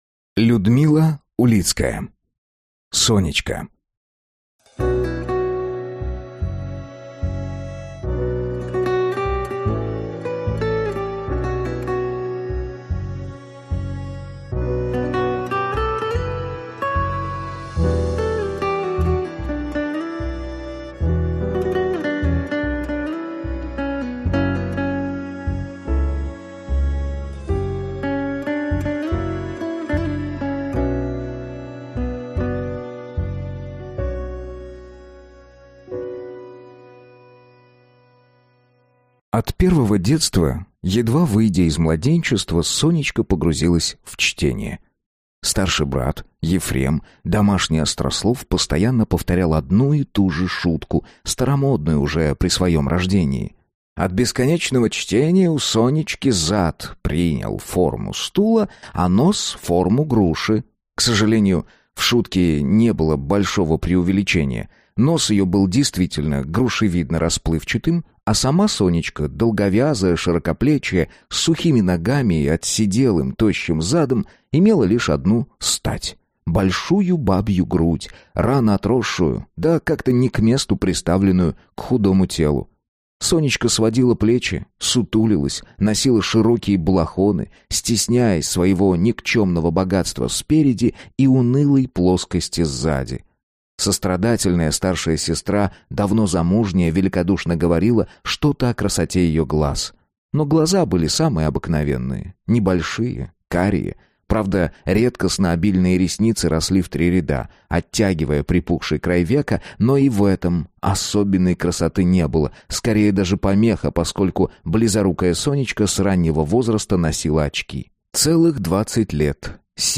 Аудиокнига Сонечка | Библиотека аудиокниг